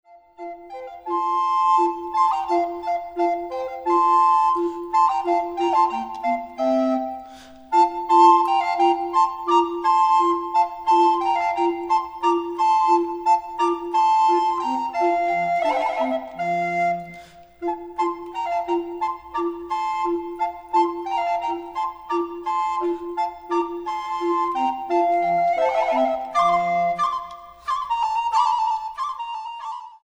flautas de pico